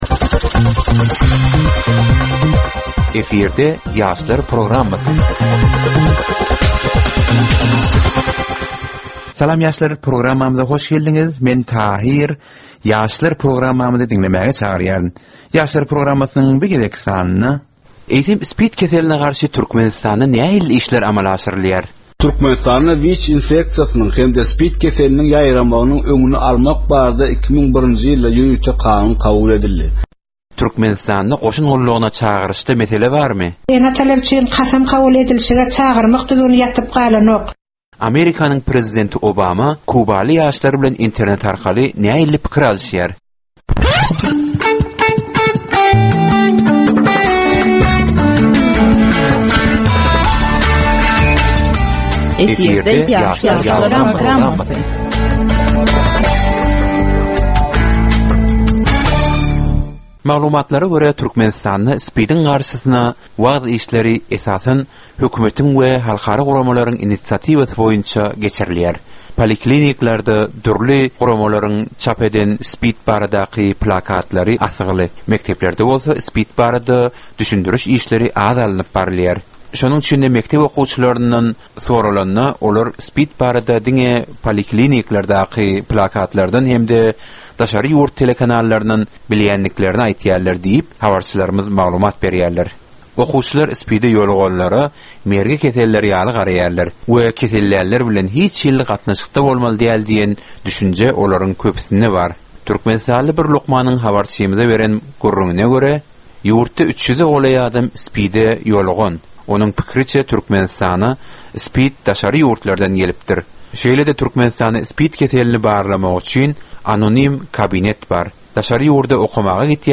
Gepleşigiň dowmynda aýdym-sazlar hem eşitdirilýär.